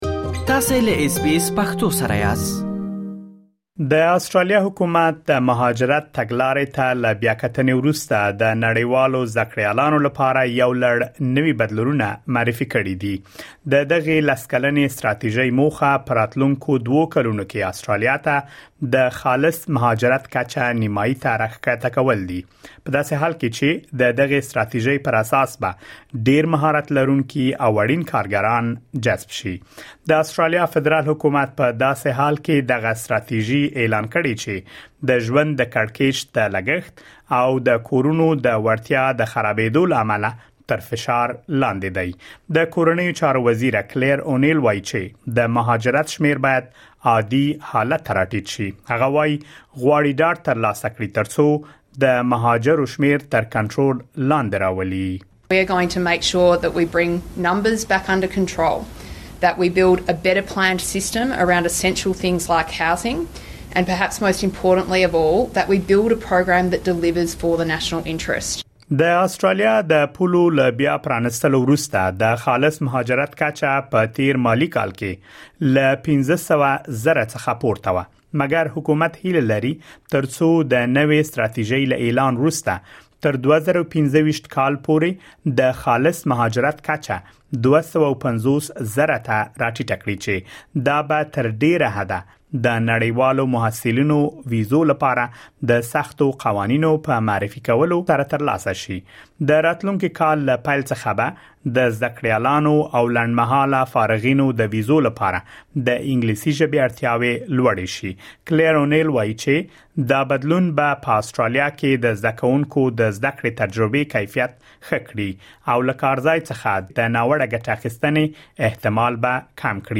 حکومت غواړي په راتلونکو دوو کلونو کې د خالص مهاجرت کچه نیمايي ته را ښکته شي. مهرباني وکړئ په دې اړه لا ډېر معلومات په رپوټ کې واورئ.